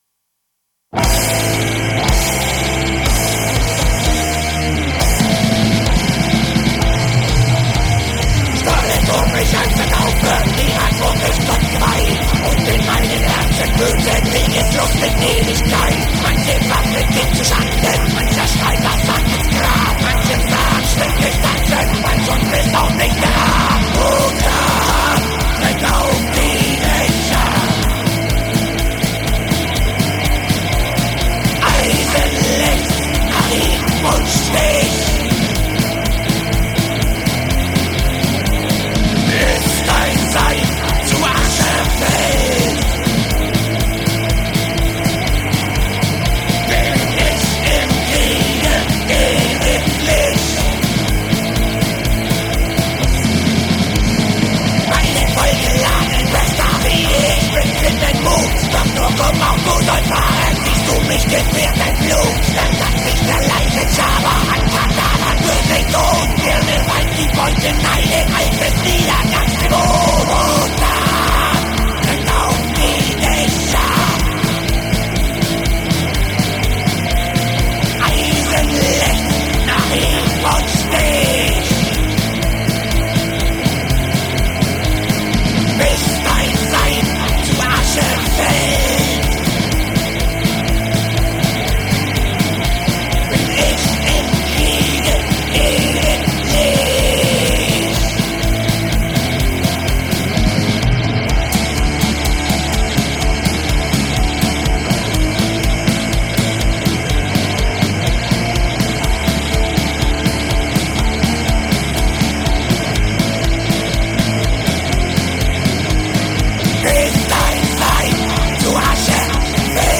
Rezension Promotion Black und Pagan Metal
Rock lastiger Black Metal